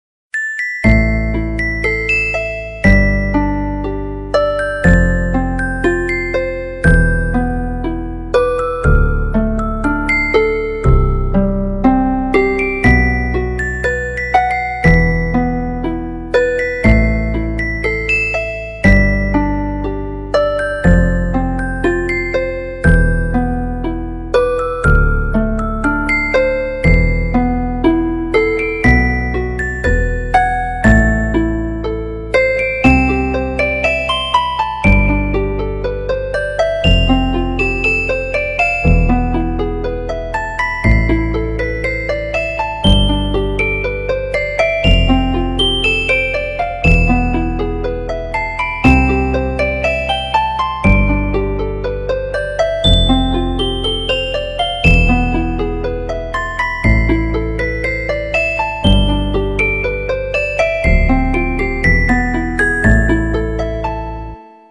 Nhạc chuông 50 lượt xem 10/02/2026